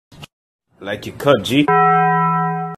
PLAY I like ya cut G with Taco Bell bong